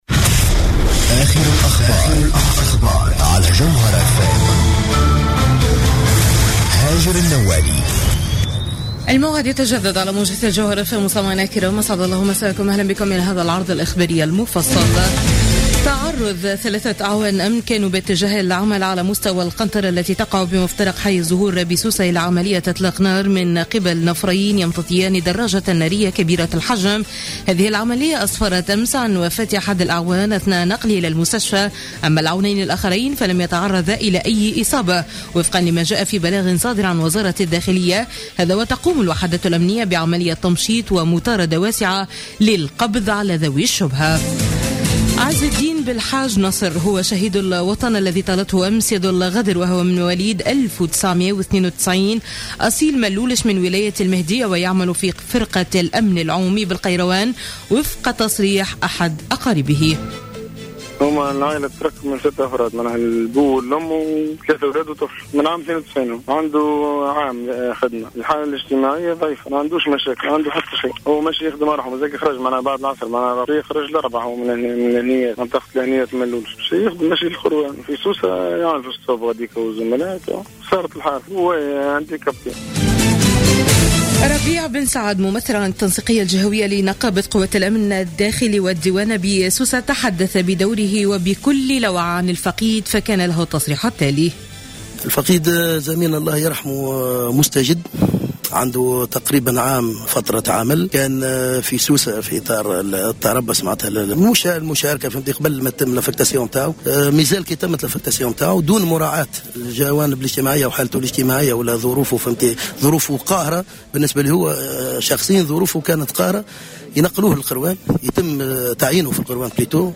نشرة أخبار منتصف الليل ليوم الخميس 20 أوت 2015